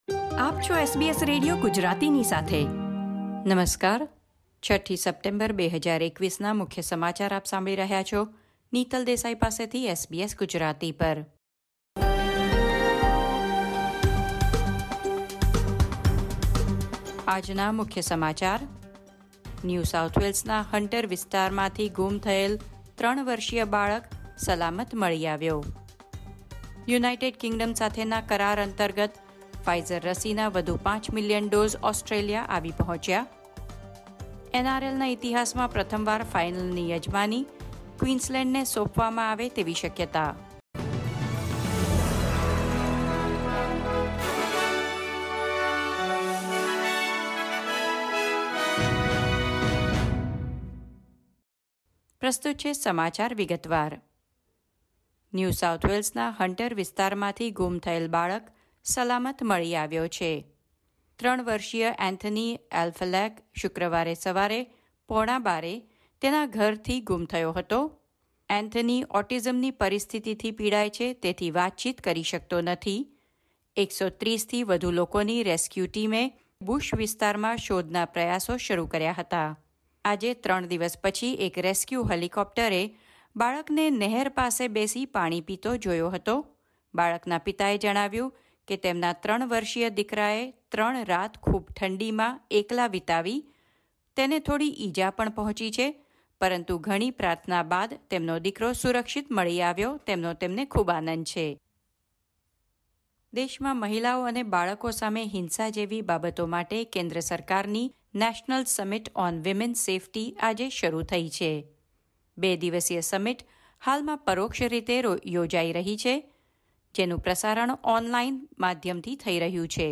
SBS Gujarati News Bulletin 6 September 2021